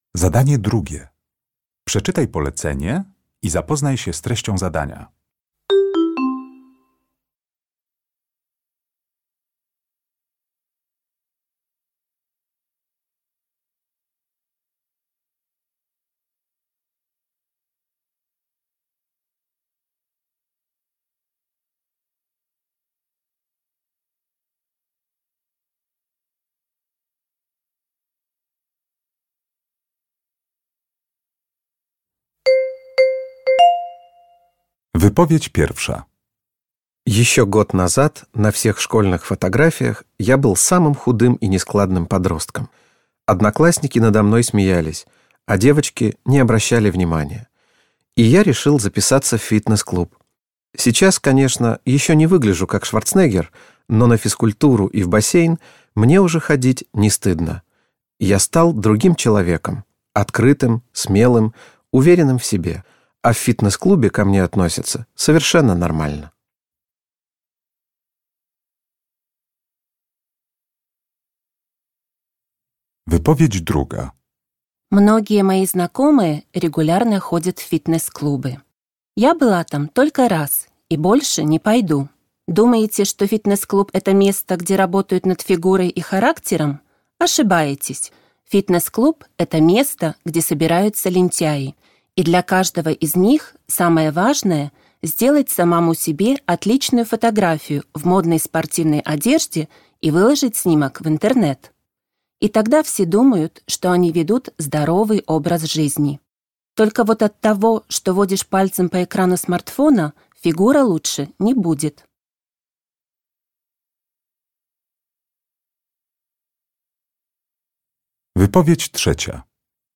Uruchamiając odtwarzacz z oryginalnym nagraniem CKE usłyszysz dwukrotnie cztery wypowiedzi na temat fitnessu.